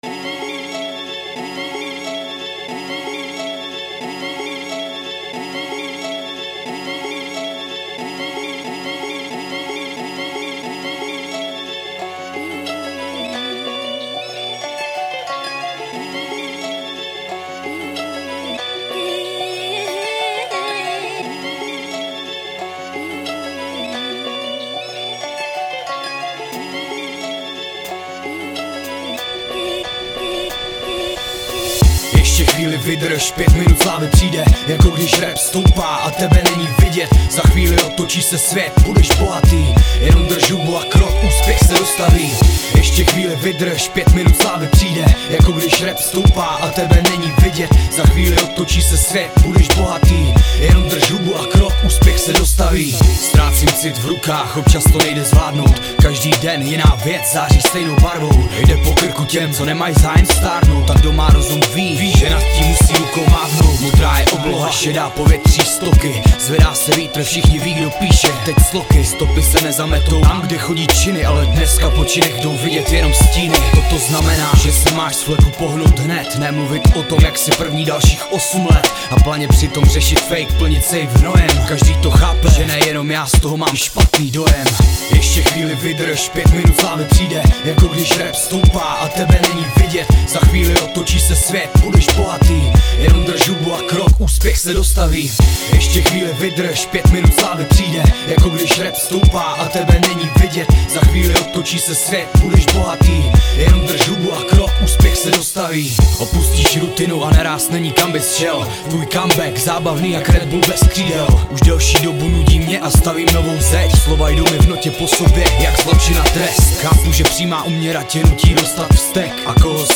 14 Styl: Hip-Hop Rok